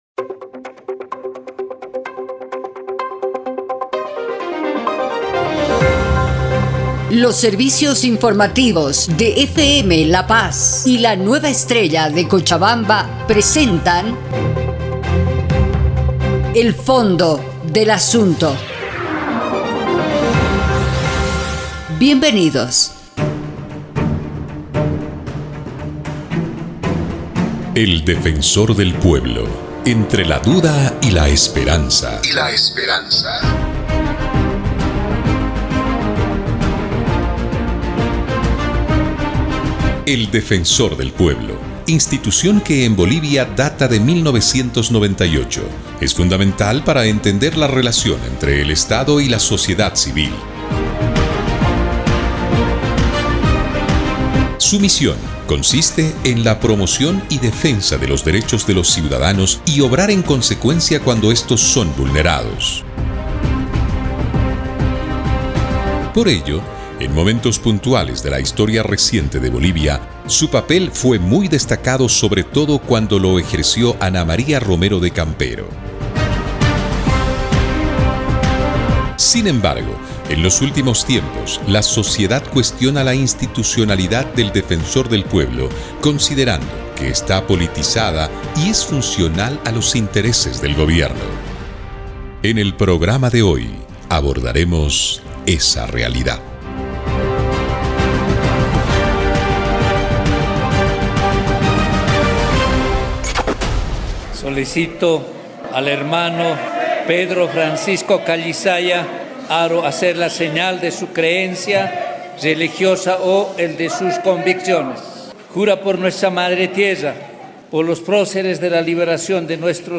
Un programa de reportajes